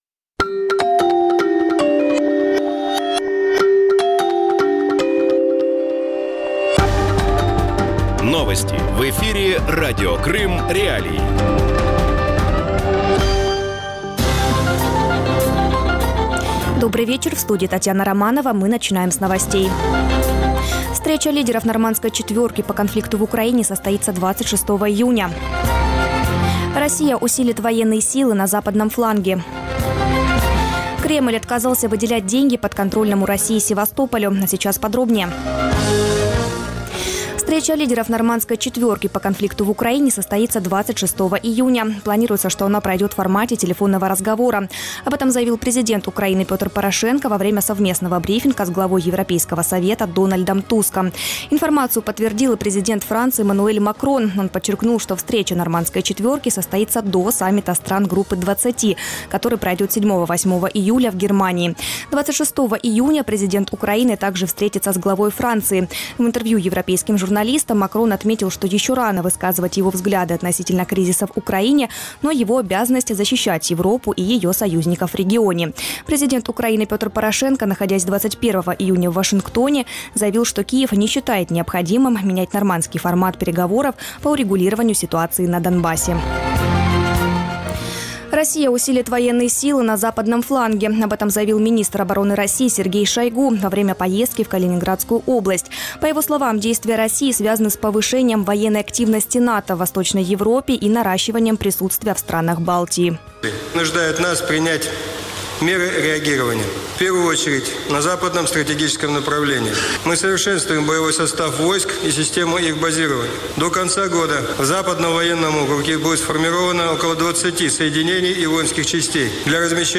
Вечерний выпуск новостей о событиях в Крыму. Все самое важное, что случилось к этому часу на полуострове.